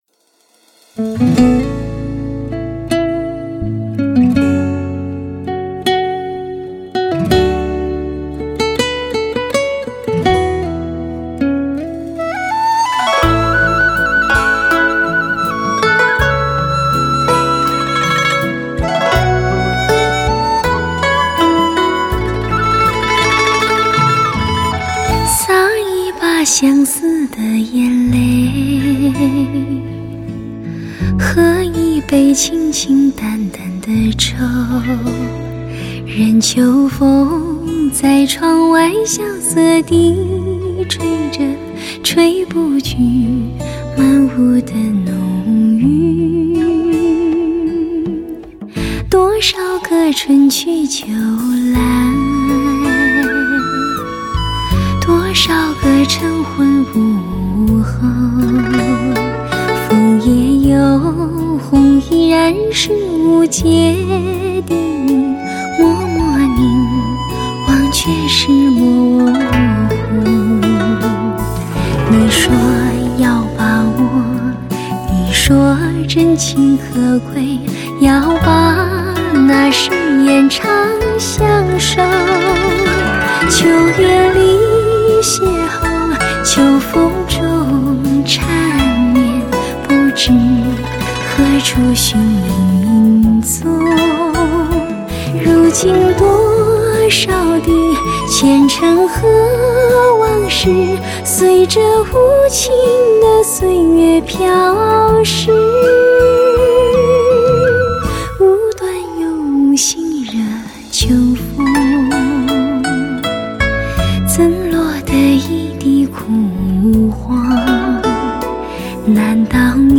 24BIT-96KHZ 纯数码录音制作